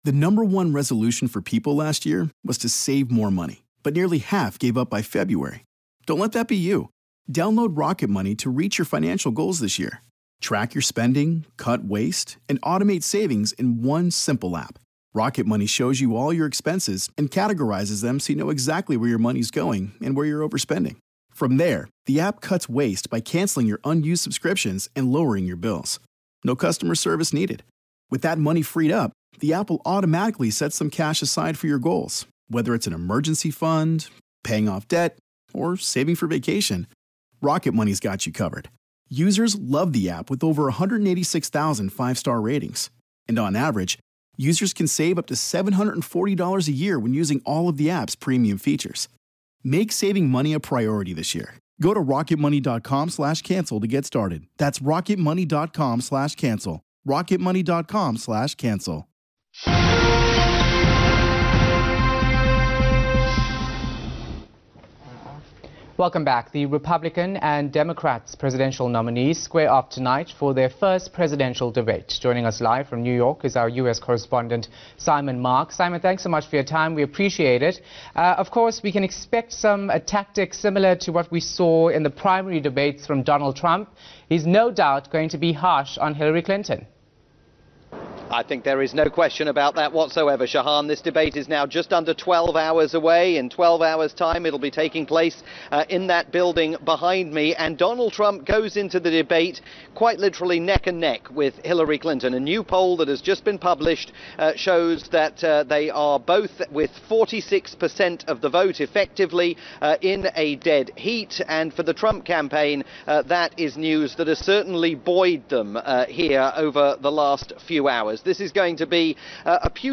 His live report aired on South Africa's top-rated news channel ENCA.